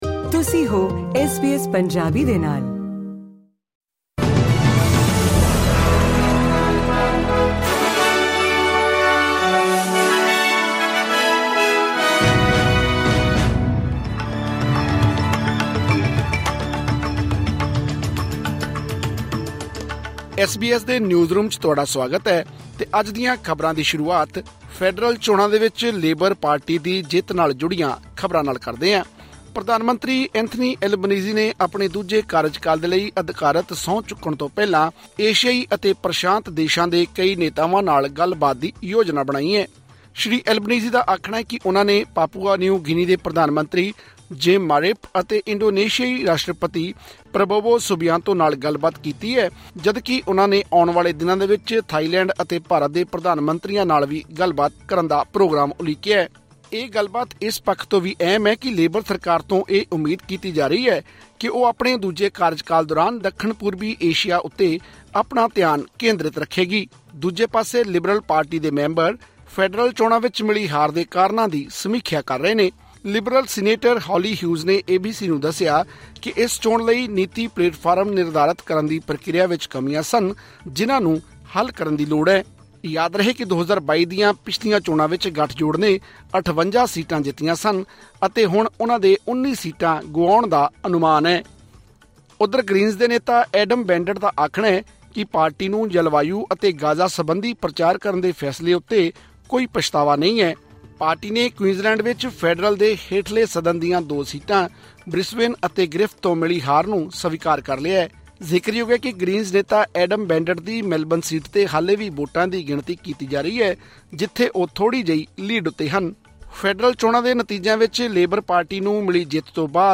ਖ਼ਬਰਨਾਮਾ : ਫੈਡਰਲ ਚੋਣਾਂ ਵਿੱਚ ਲੇਬਰ ਪਾਰਟੀ ਨੂੰ ਮਿਲੀ ਜਿੱਤ ’ਤੇ ਮੋਦੀ ਨੇ ਦਿੱਤੀਆਂ ਮੁਬਾਰਕਾਂ, ਟਰੰਪ ਨੇ ਕੀਤੀ ਟਿੱਪਣੀ